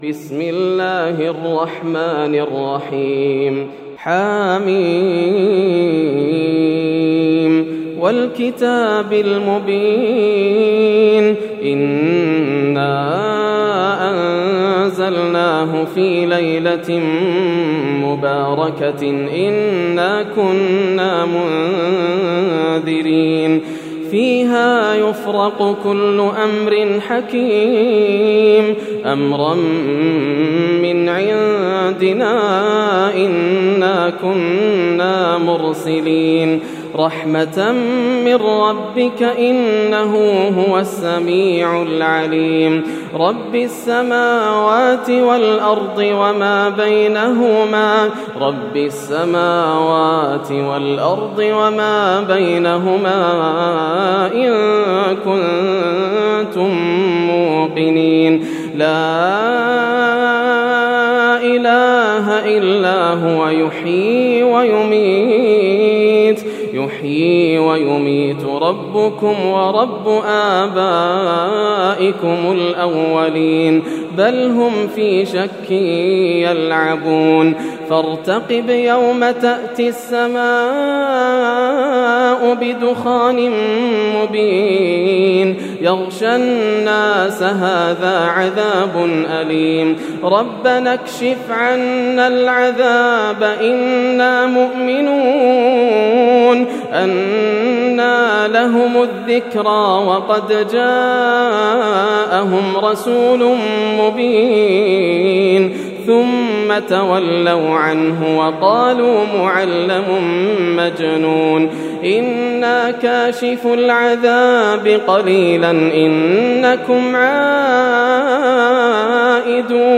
سورة الدخان > السور المكتملة > رمضان 1431هـ > التراويح - تلاوات ياسر الدوسري